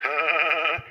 Magellan Laugh Botón de Sonido